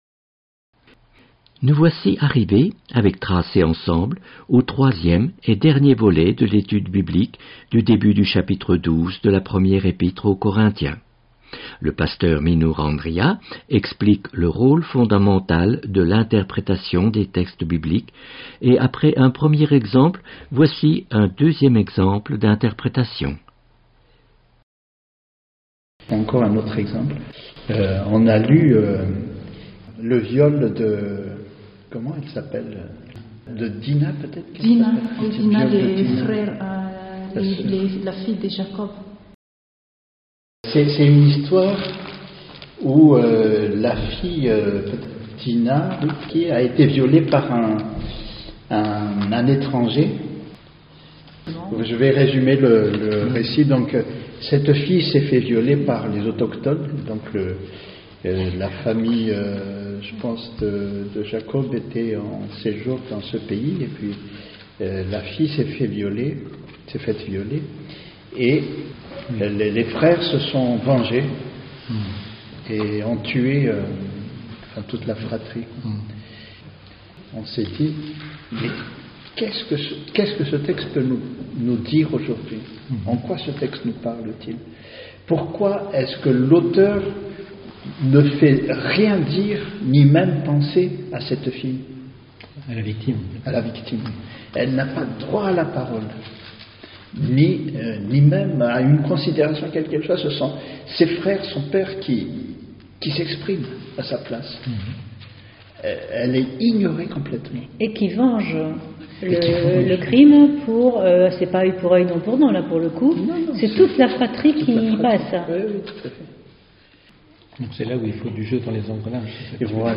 Etude biblique